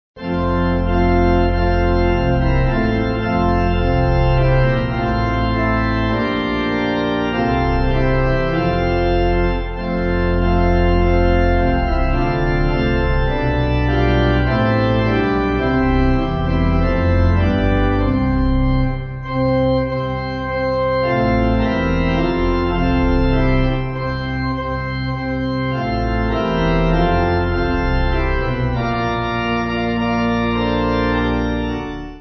7.7.7.7.D with refrain
Organ